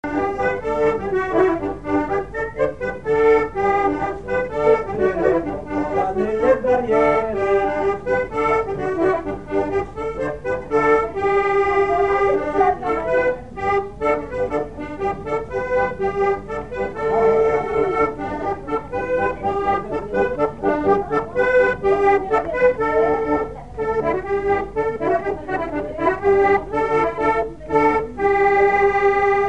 danse : ronde : grand'danse
Genre laisse
Pièce musicale inédite